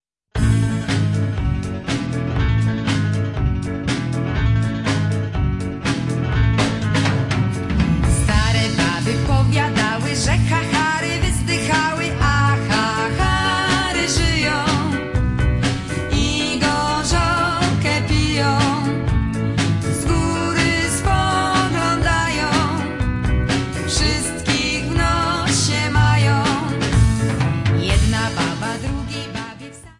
Polish folk songs